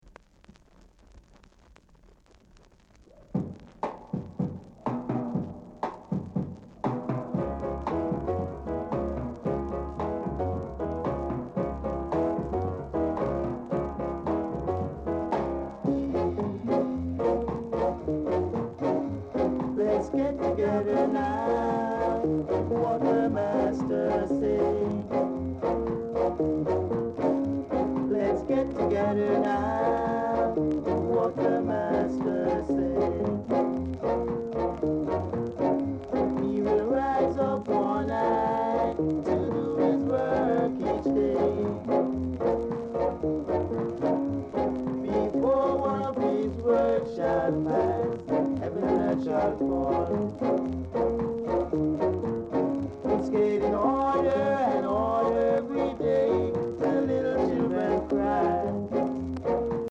R.Steady Vocal Group